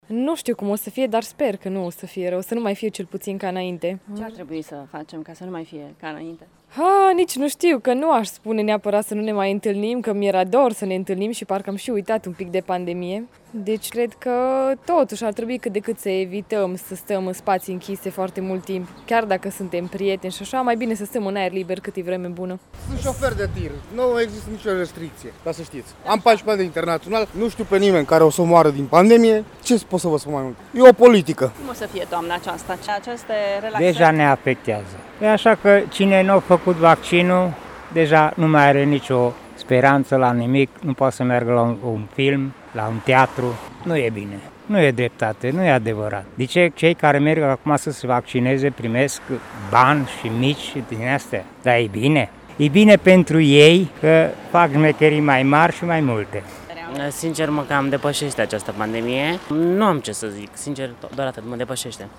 Unii târgumureșeni recunosc că aproape au uitat de pandemie și cred că realitatea e diferită de ceea ce spun specialiștii: